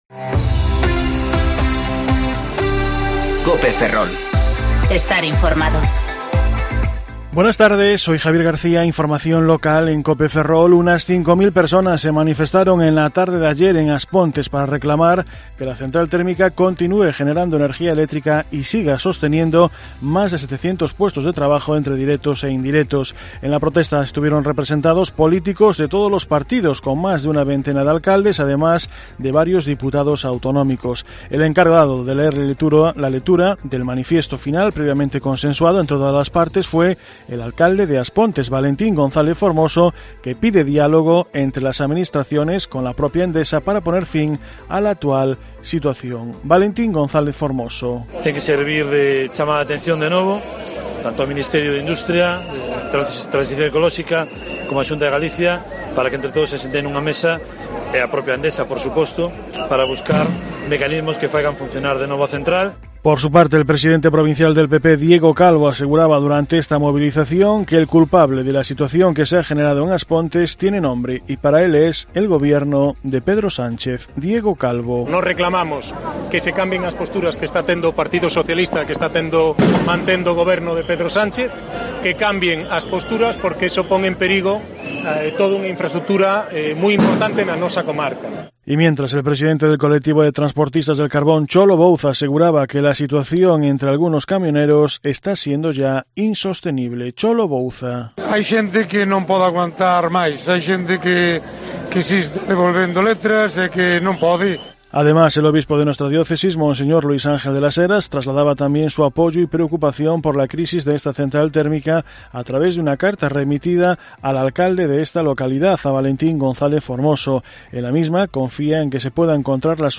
Informativo Mediodía Cope Ferrol 20/9/2019